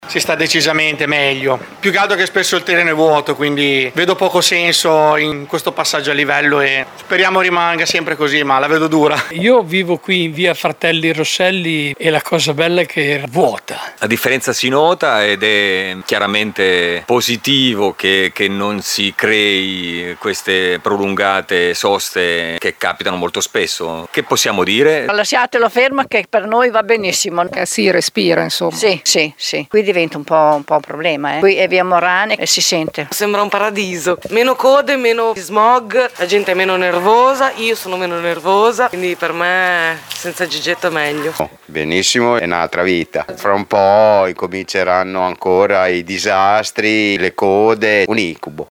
Le interviste tra chi vive e lavora in via Fratelli Rosselli e Morane: